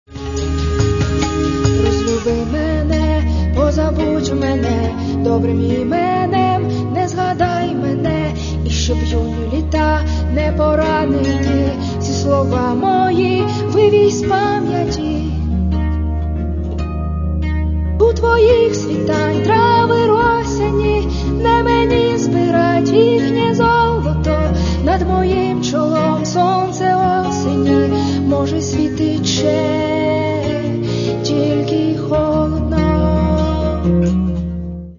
Каталог -> MP3-CD -> Альтернатива